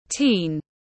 Thanh thiếu niên tiếng anh gọi là teen, phiên âm tiếng anh đọc là /tiːn/.
Teen /tiːn/